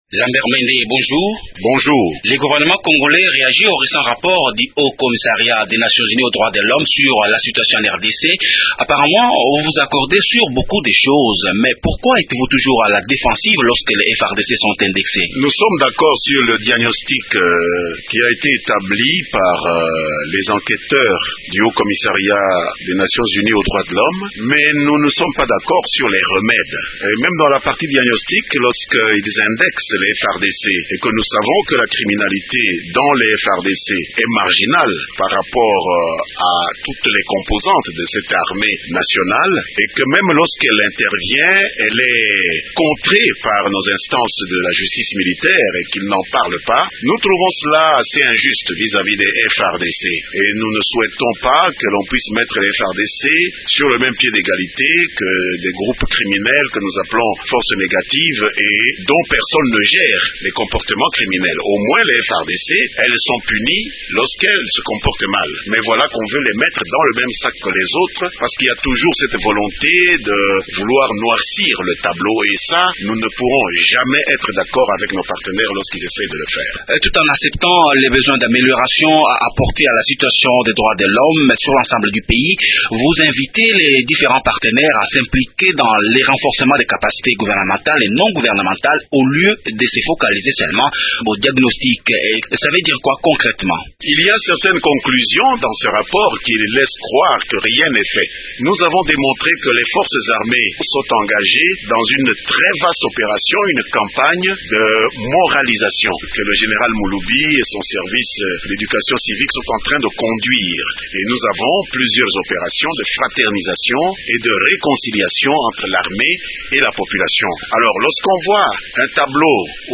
Lambert Mende, Ministre de l'information et porte parole du gouvernement